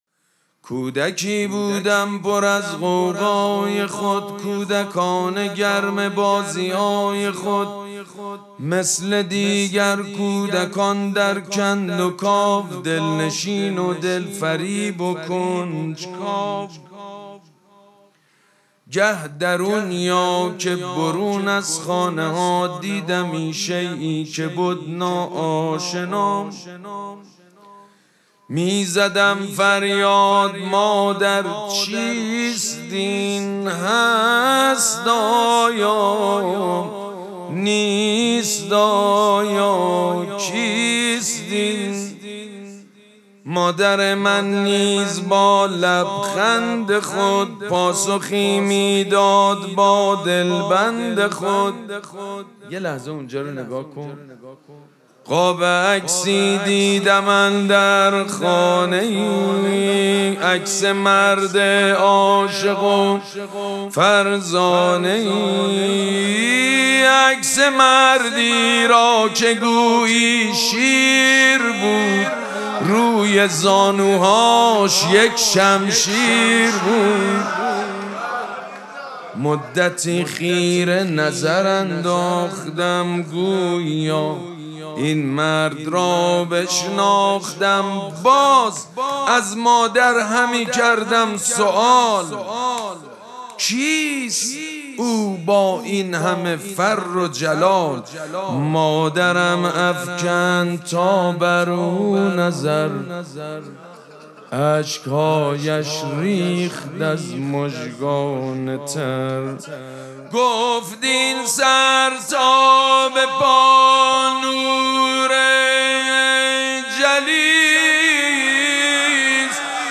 مراسم مناجات شب بیست و دوم ماه مبارک رمضان
حسینیه ریحانه الحسین سلام الله علیها
شعر خوانی
حاج سید مجید بنی فاطمه